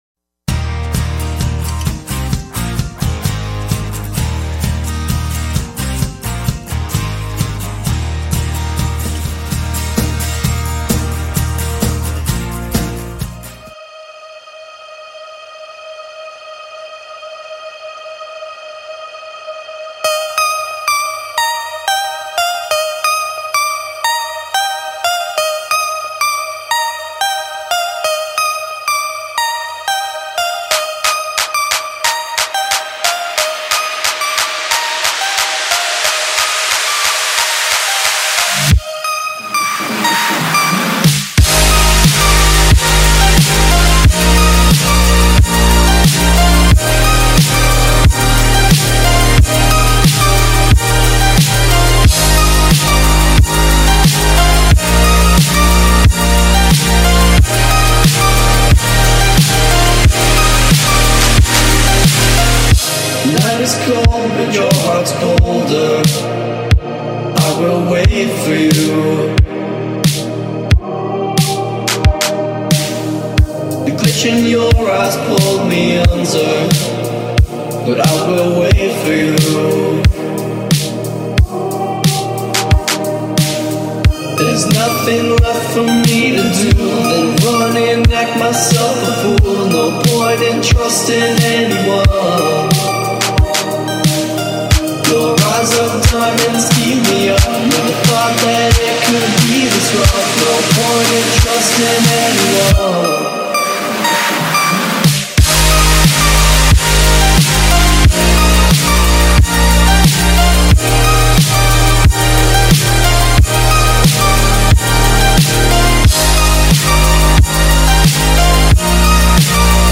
motivetional background gaming music